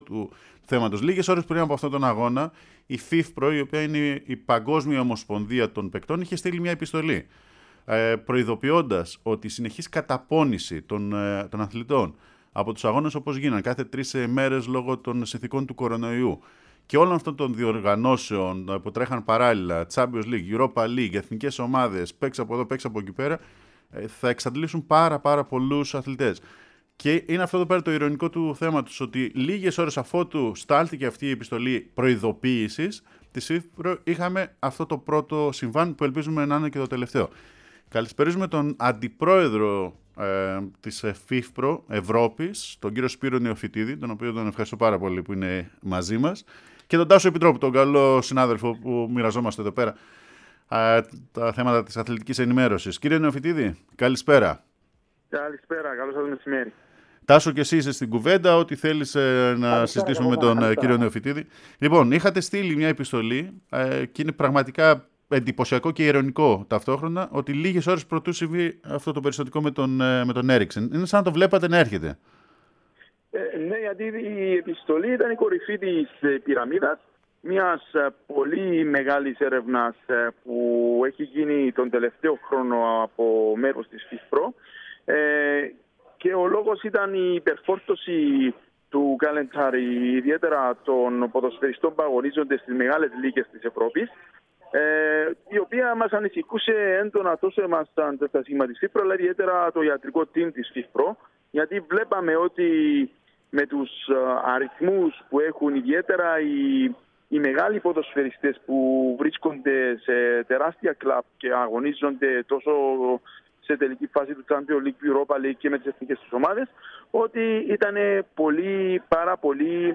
μίλησε στη εκπομπή Εχουμε και Λέμε του Πρώτου Προγράμματος της Ελληνικής Ραδιοφωνίας